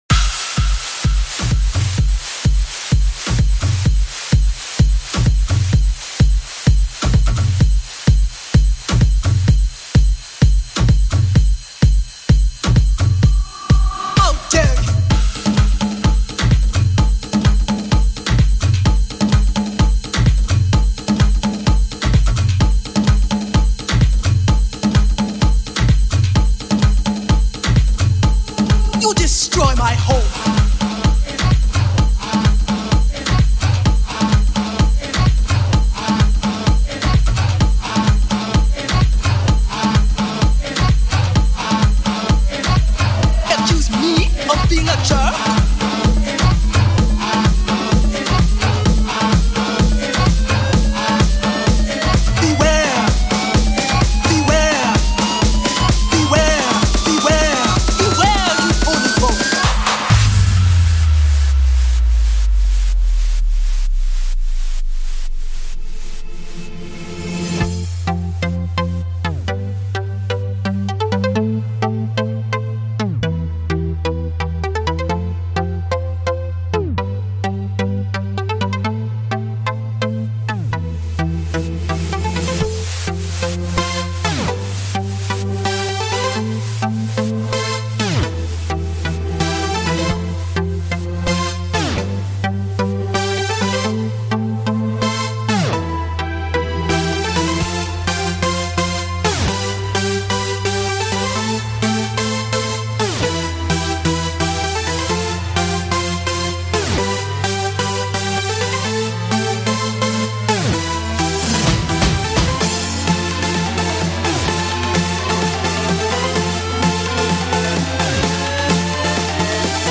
I'm back with another, yes another, Bigroom House track.